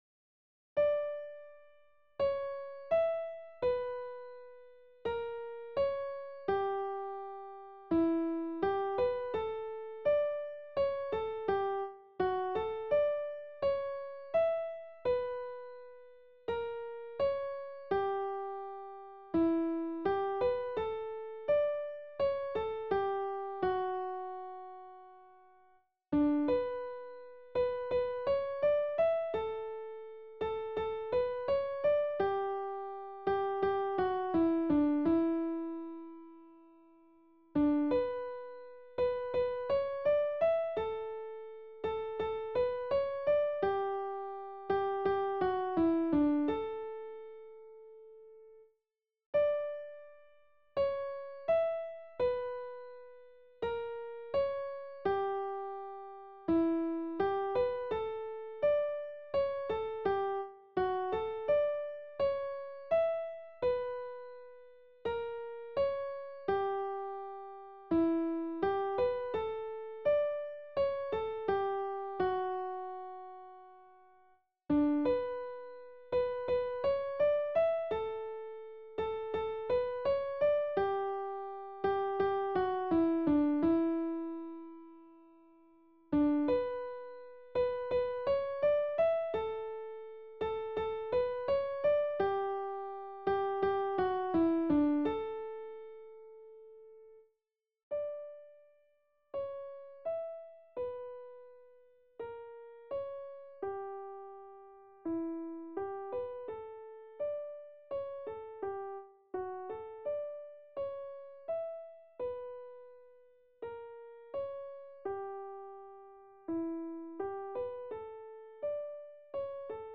Noël fait danser les couleurs Soprano - Chorale Concordia 1850 Saverne
Noël-fait-danser-les-couleurs-Soprano.mp3